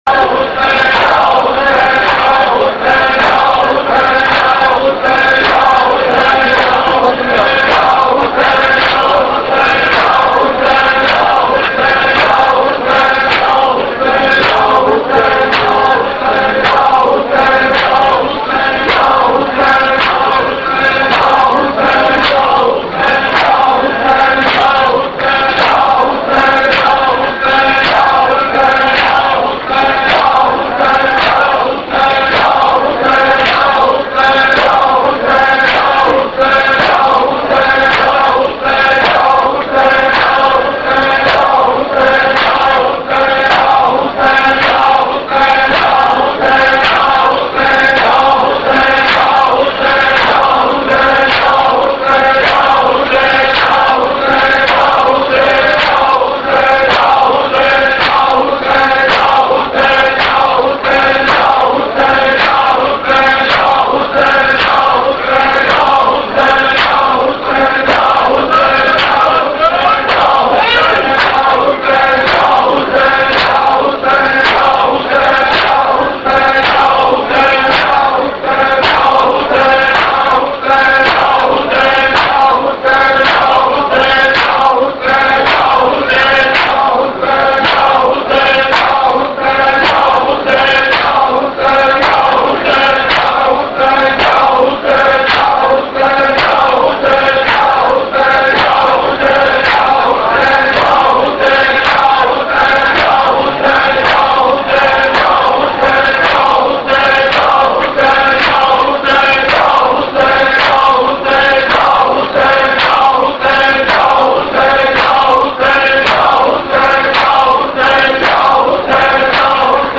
Markazi Matmi Dasta, Rawalpindi
Recording Type: Live Year: 2006, 2 Moharram